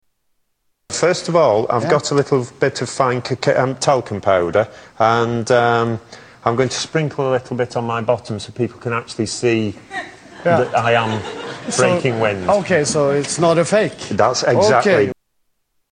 Tags: Comedians Mr Methane Fart Fart Music Paul Oldfield